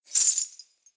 Danza árabe, bailarina mueve un sujetador con monedas 02: golpe
agitar
Sonidos: Acciones humanas